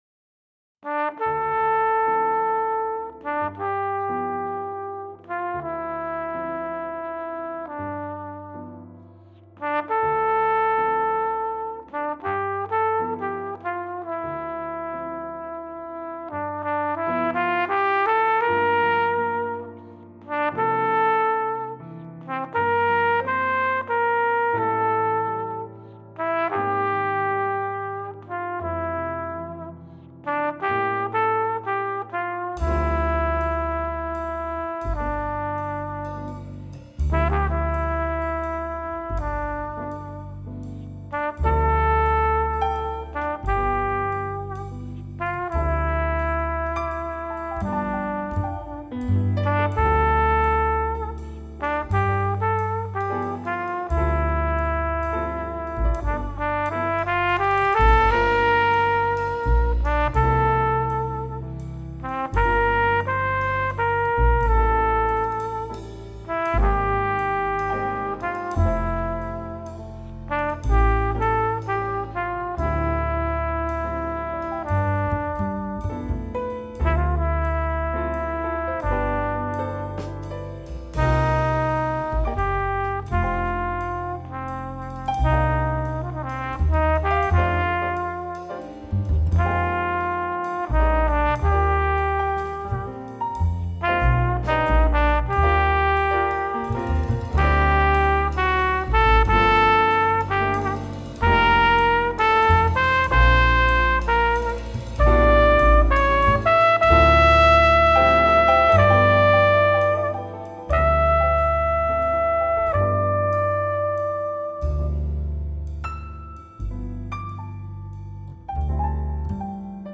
tromba
pianoforte
chitarra
contrabbasso
batteria